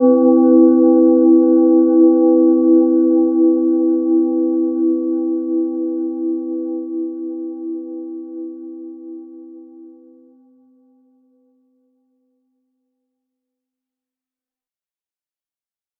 Gentle-Metallic-2-E4-p.wav